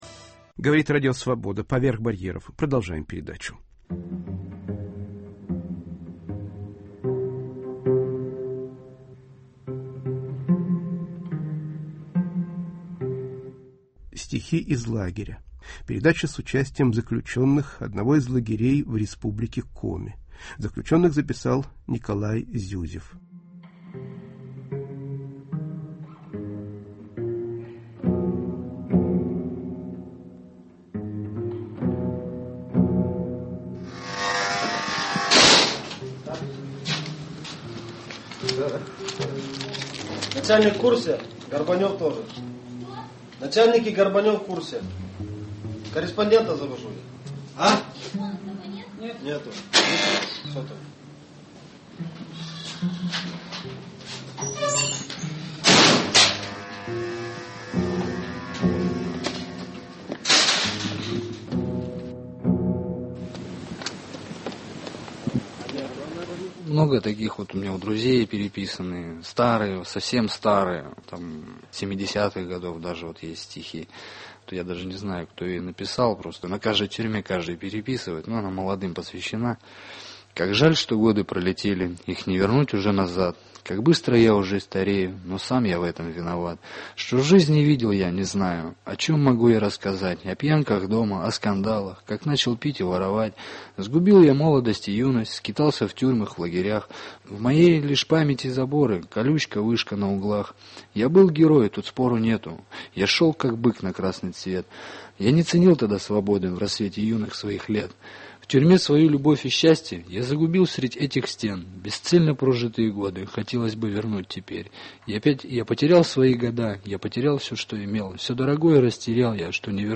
"Лагерная поэзия".Стихи, записанные в лагере строгого режима.